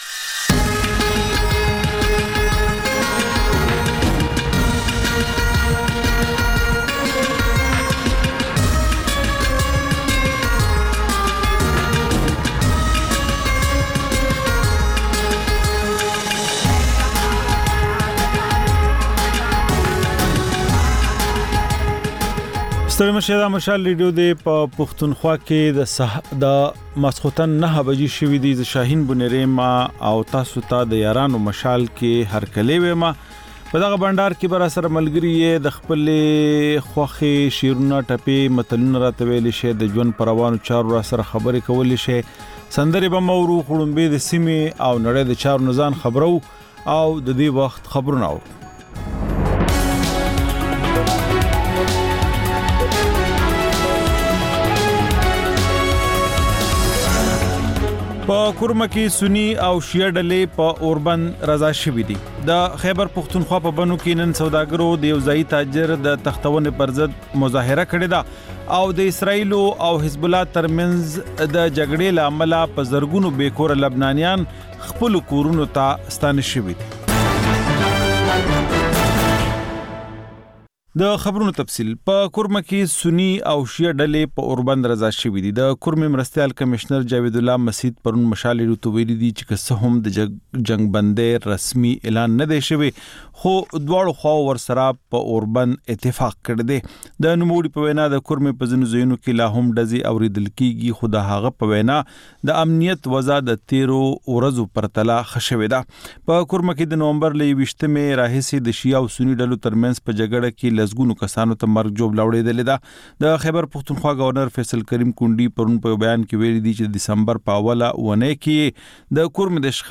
د یارانو مشال په ژوندۍ خپرونه کې له اورېدونکو سره بنډار لرو او سندرې خپروو.